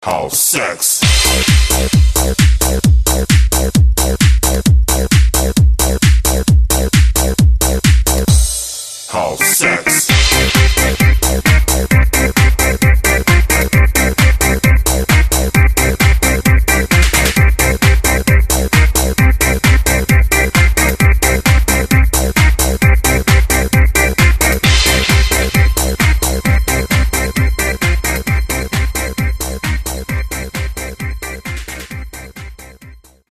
AUS DEM DANCE BEREICH . . .